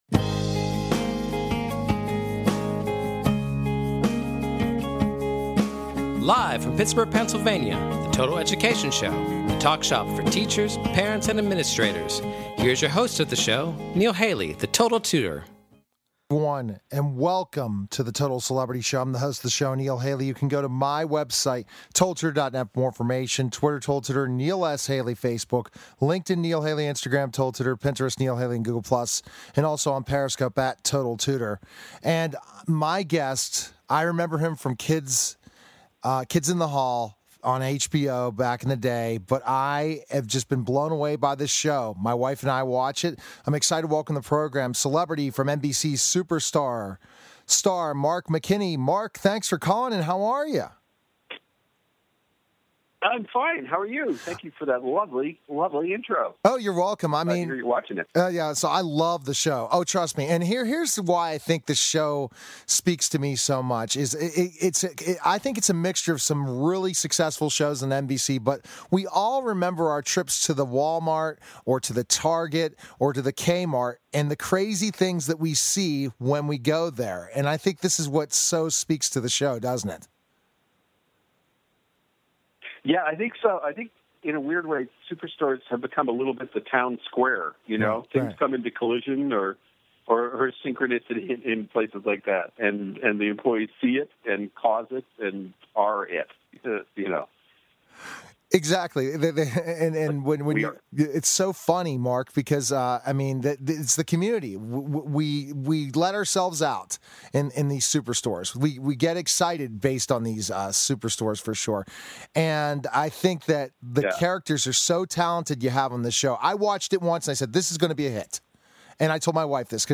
Total Education Celebrity Show {also known as "The Total Education Hour" is an educational talk show that focuses on the listeners' needs. Catch weekly discussions focusing on current education news at a local and national scale.